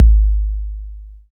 KIK 808 K 2.wav